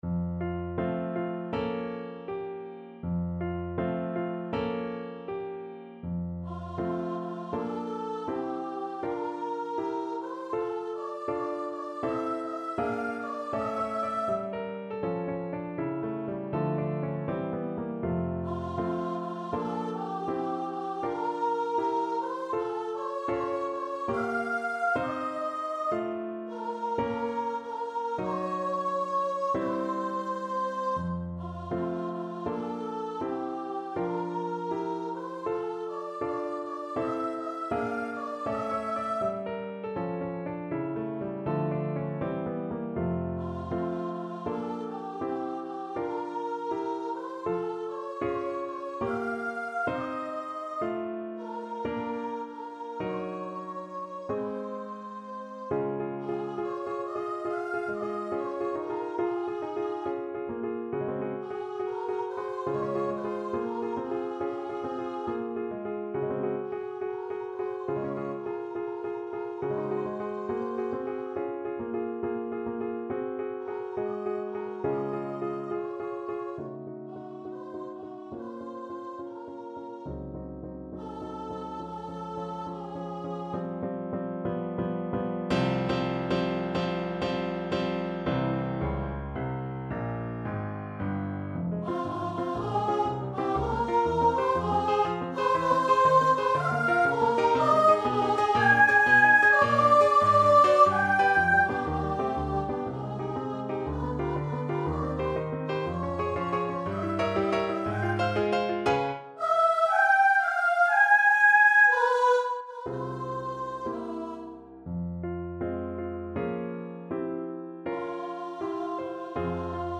Moderato =80
2/4 (View more 2/4 Music)
Classical (View more Classical Voice Music)